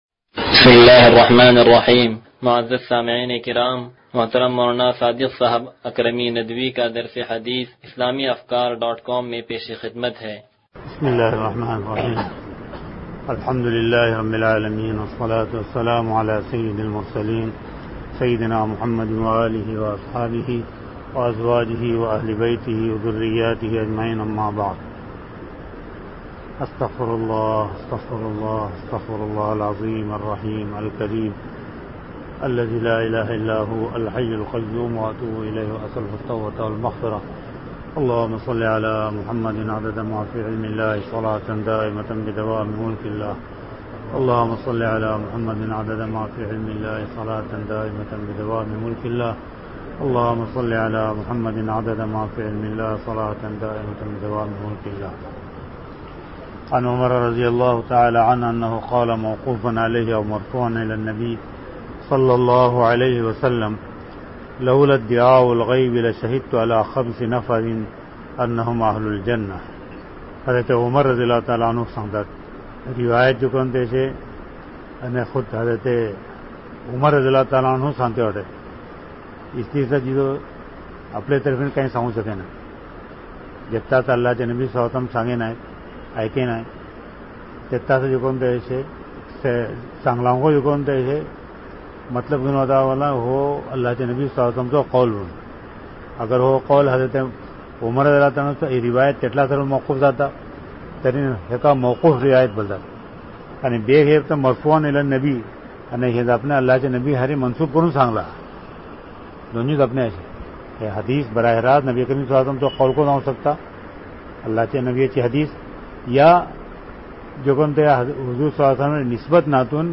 درس حدیث نمبر 0107
(تنظیم مسجد)